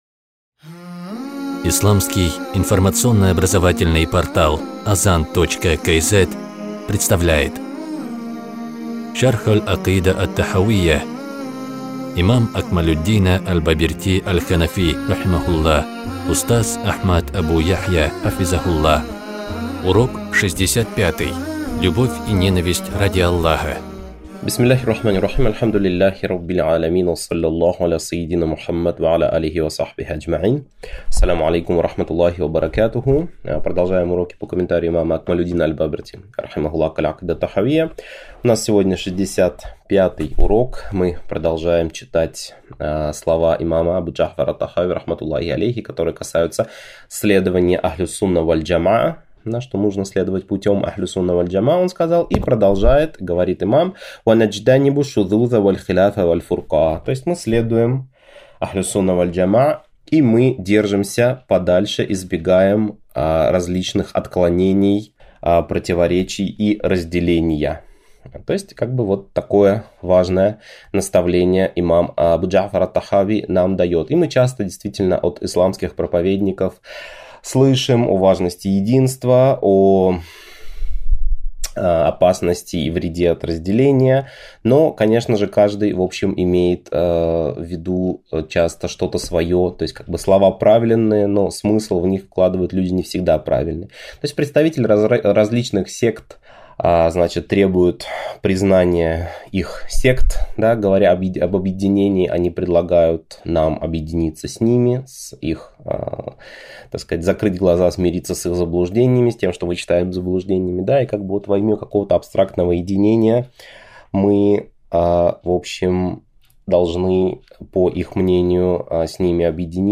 Цикл уроков по акыде второго уровня сложности, рассчитанный на слушателя, освоившего основы акыды. В комментарии имама аль-Бабирти положения акыды разбираются более углубленно, приводятся доказательства из Корана и Сунны, разбираются разногласия ашаритов и матуридитов с другими исламскими течениями.